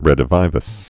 (rĕdə-vīvəs, -vē-)